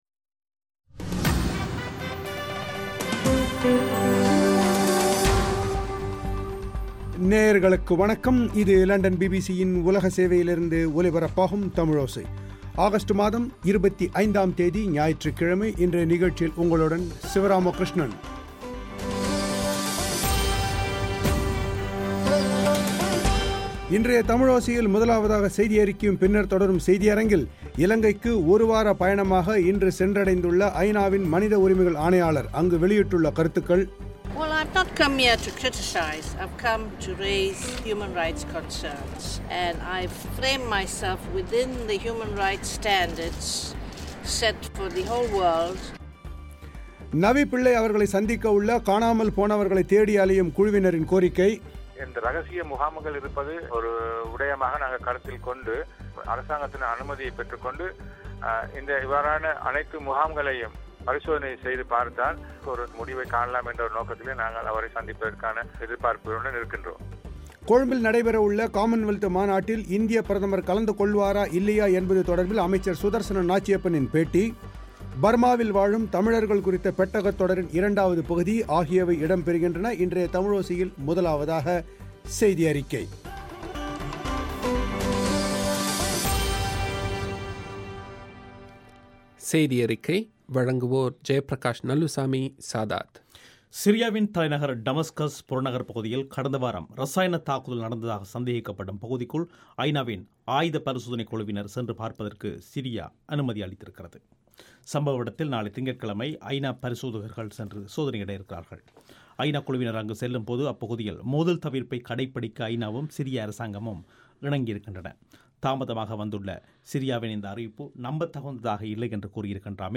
கொழும்பில் நடைபெறவுள்ள காமன்வெல்த் மாநாட்டில் இந்தியப் பிரதமர் கலந்து கொள்வாரா இல்லையா என்பது தொடர்பில் அமைச்சர் சுதர்சன நாச்சியப்பனின் பேட்டி. பர்மாவின் வாழும் தமிழர்கள் குறித்த பெட்டகத் தொடரின் இரண்டாவது பகுதி.